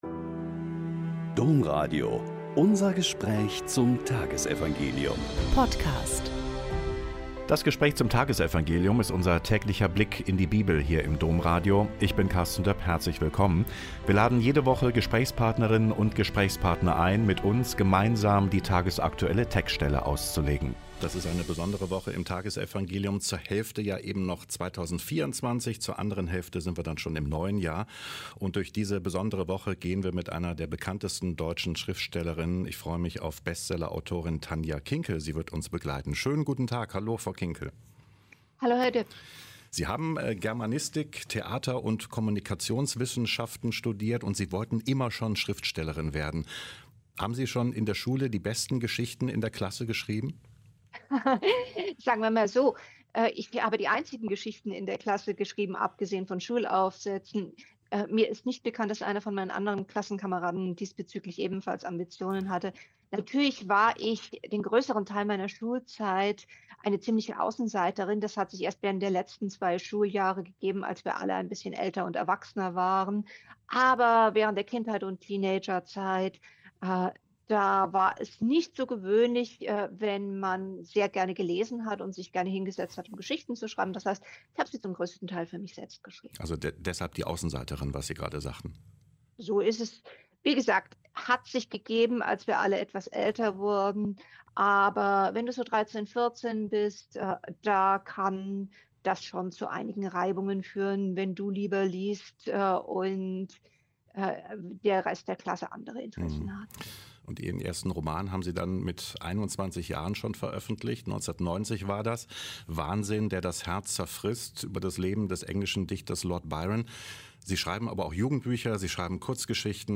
Lk 2,36-40 - Gespräch mit Dr. Tanja Kinkel